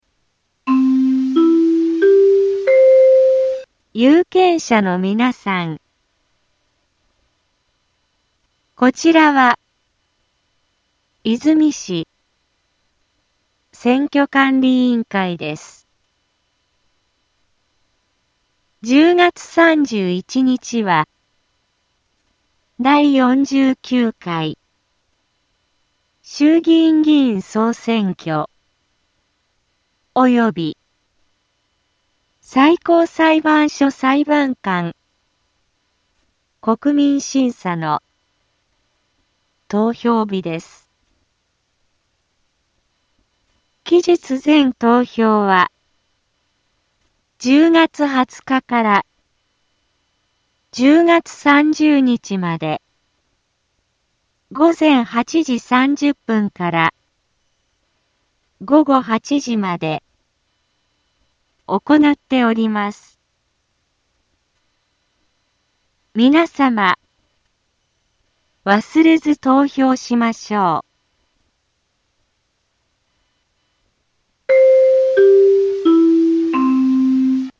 BO-SAI navi Back Home 災害情報 音声放送 再生 災害情報 カテゴリ：通常放送 住所：大阪府和泉市府中町２丁目７−５ インフォメーション：有権者のみなさん こちらは和泉市選挙管理委員会です １０月３１日は、第４９回衆議院議員総選挙及び最高裁判所裁判官国民審査の投票日です 期日前投票は、１０月２０日から１０月３０日まで午前８時３０分から午後８時まで行なっております 皆様、忘れず投票しましょう